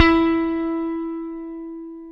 Index of /90_sSampleCDs/AKAI S-Series CD-ROM Sound Library VOL-7/JAZZY GUITAR
JAZZ GT1E3.wav